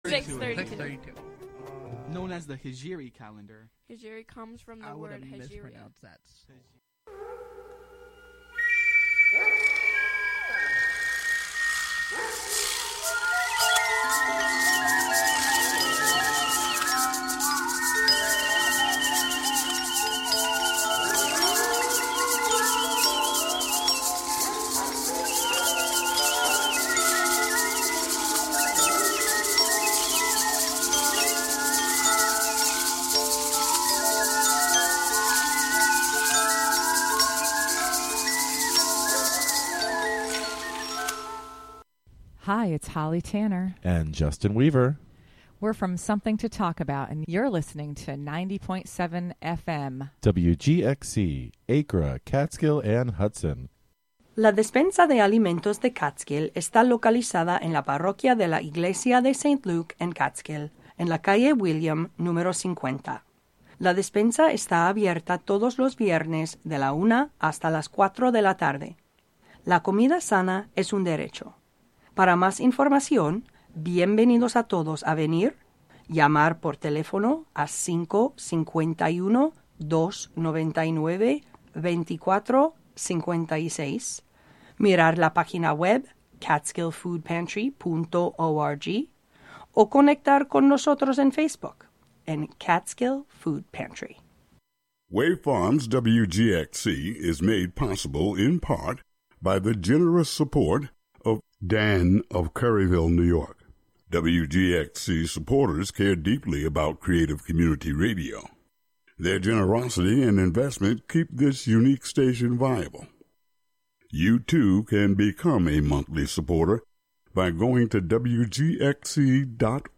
Featuring gospel, inspirational, soul, R&B, country, christian jazz, hip hop, rap, and praise and worship music of our time and yesteryear, interwoven with talk, interviews, and spiritual social commentary